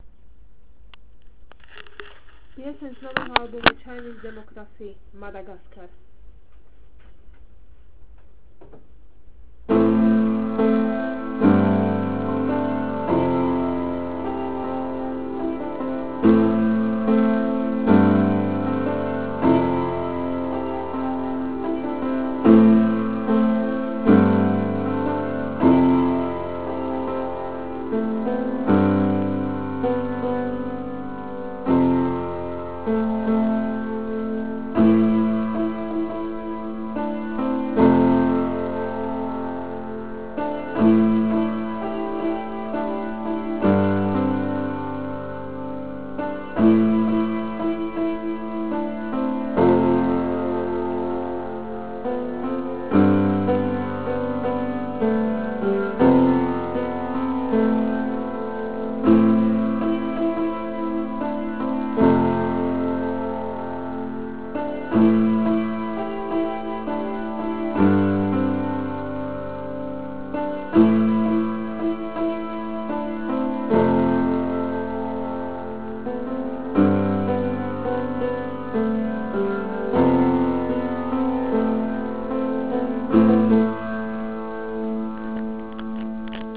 ako hrám na klavíri